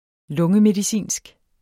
Udtale [ ˈlɔŋəmediˌsiˀnsg ]